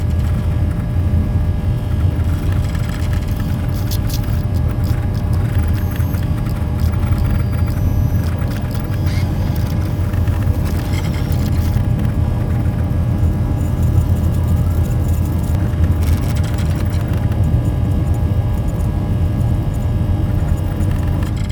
cockpit.ogg